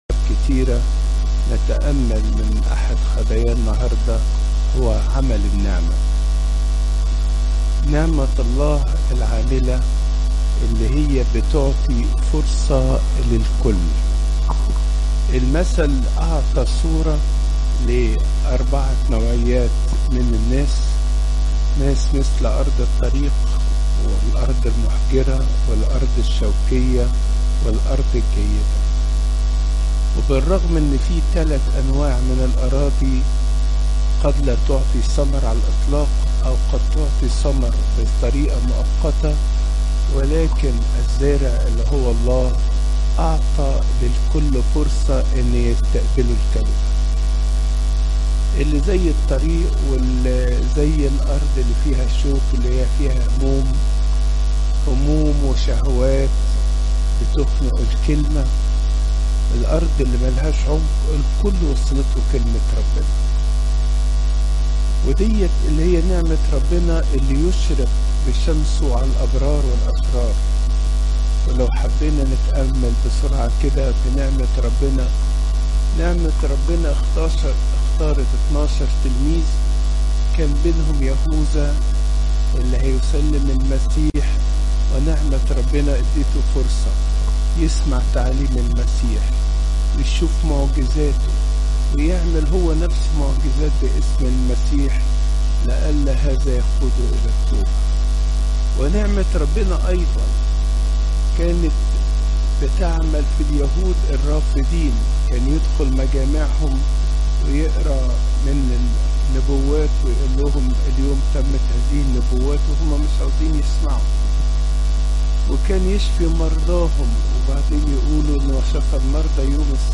تفاصيل العظة
عظات قداسات الكنيسة